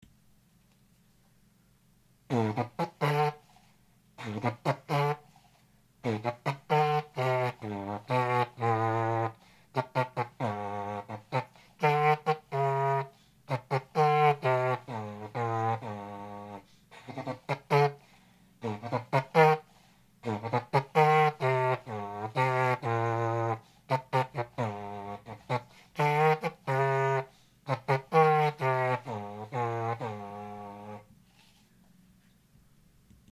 トロンボーンからヒントを得て作りました。延長管をスライドさせることによって音の高さが変えられます。